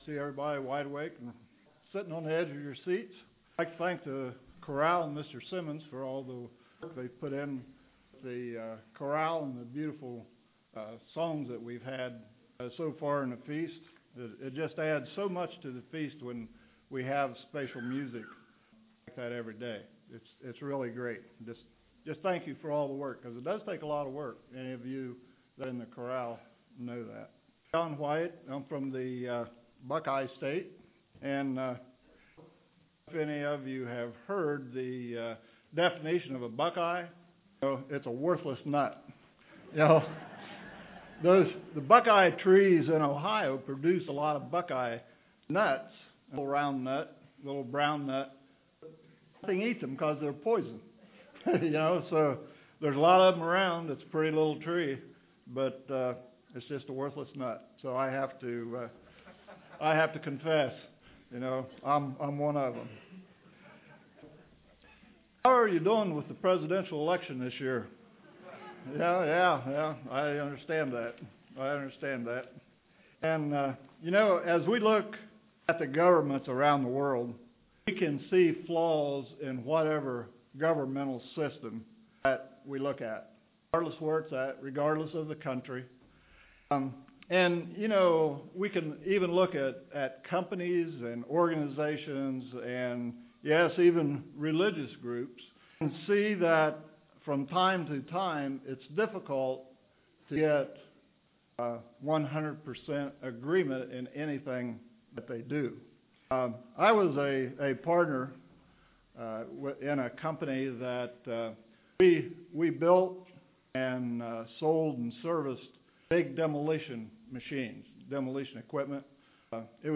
This sermon was given at the Galveston, Texas 2016 Feast site.